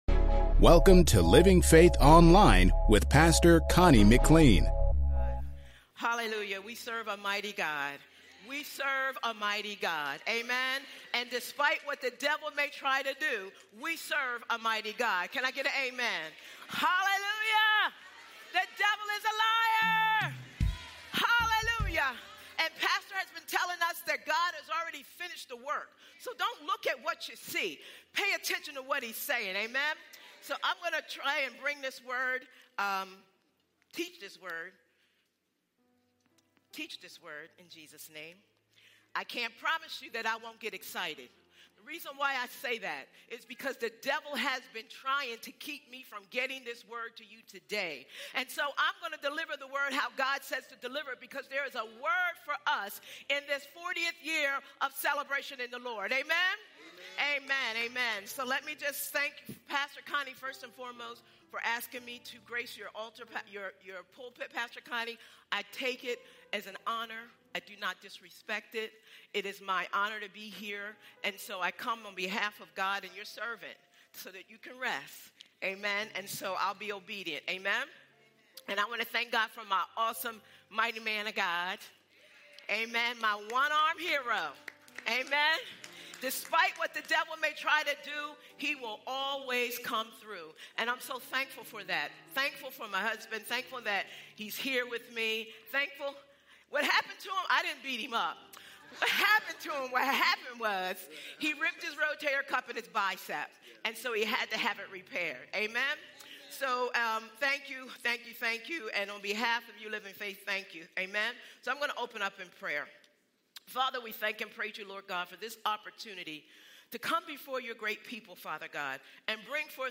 Sermons | Living Faith Christian Center